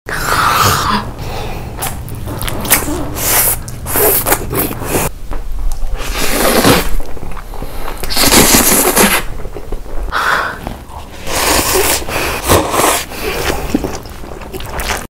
red ♥ color food mukbang sound effects free download
Korean ASMR Testing mukbang Eating Sounds